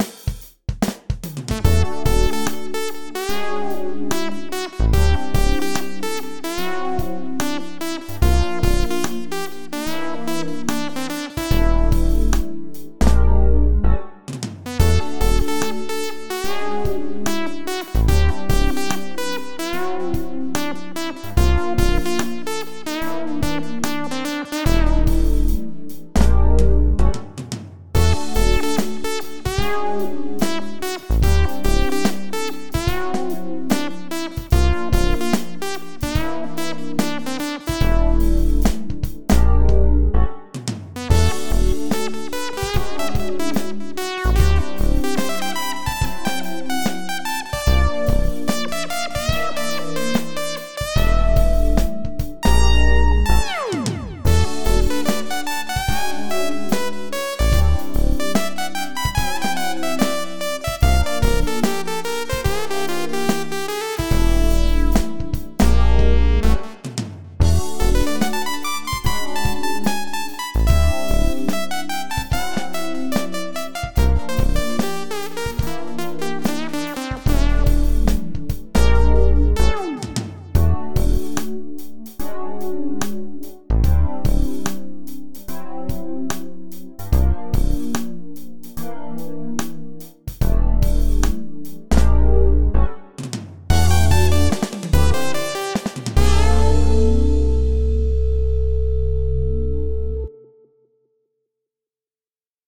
A thing at 73.8 BPM.
Contains bass, drums (completely unquantized for the the majority of the piece), e-piano, and a synth lead sound with a resonant low pass filter, all stock stuff.